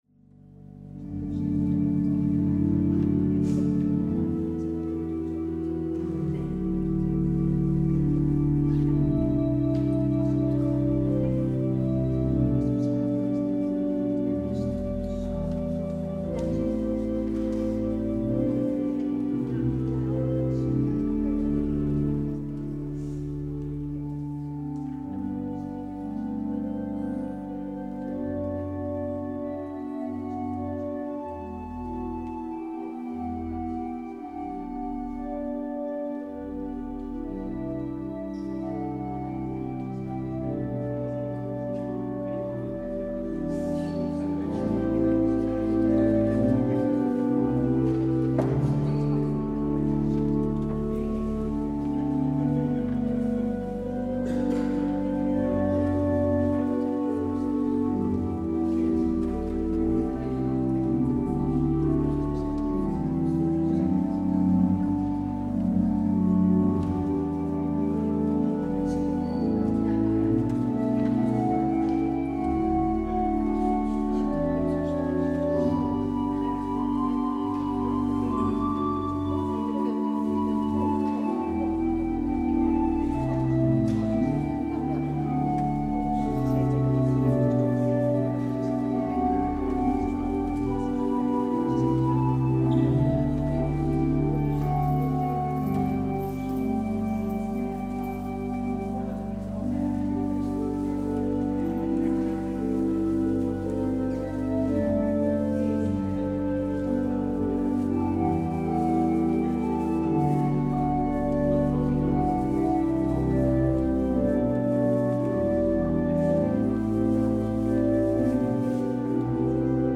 Het openingslied is: NLB 150 a: 1 en 2.
Het slotlied is: NLB 801: 1 – 5.